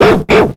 Cri de Couafarel dans Pokémon X et Y.